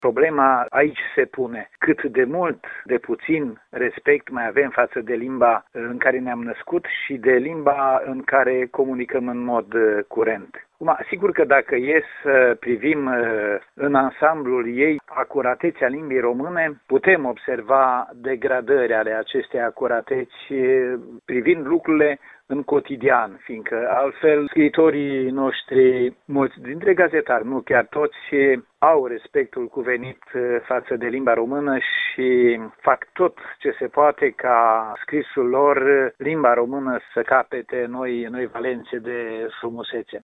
Directorul Direcției pentru Cultură, Culte și Patrimoniu Național Mureș, Nicolae Băciuț, este de părere că trebuie să dovedim respect față de limba română în fiecare zi, prin felul în care vorbim și scriem.